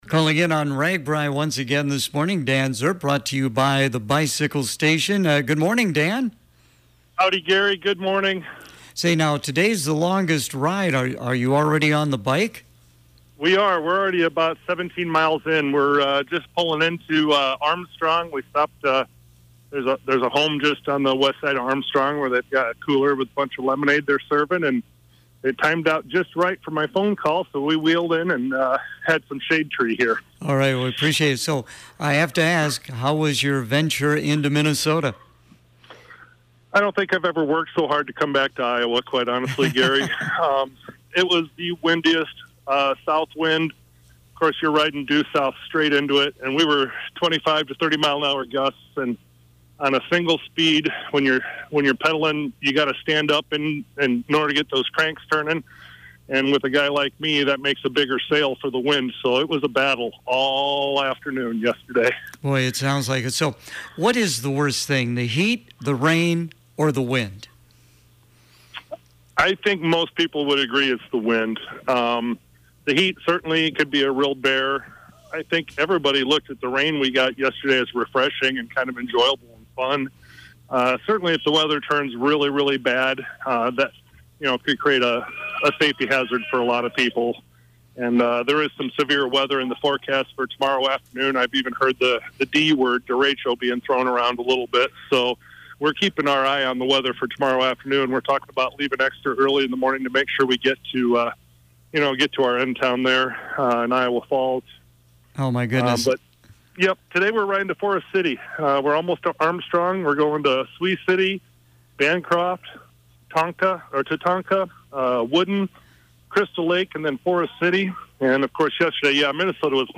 Clinton County Supervisor Dan Srp is once again riding on Ragbrai this year and is keeping listeners informed about this unique Iowa event.
If you missed it on the air, todays report is posted below.